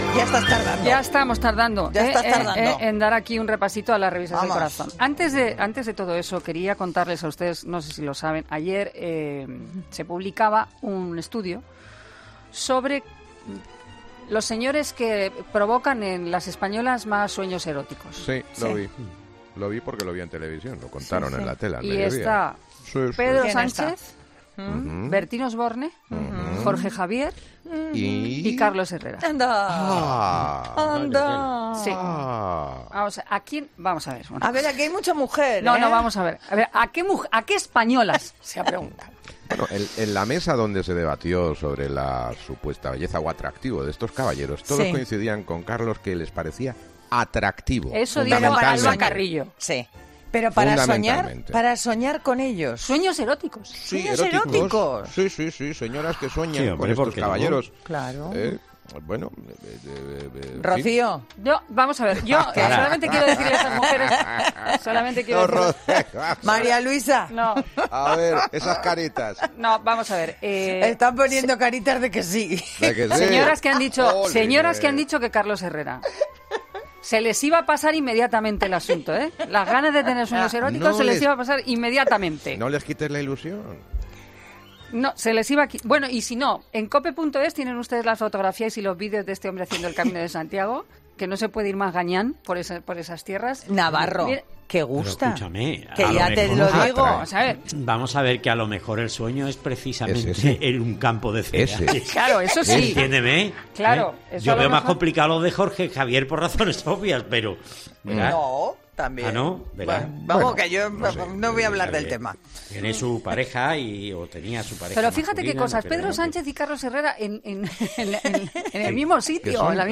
El atractivo de Carlos Herrera y su Camino de Santiago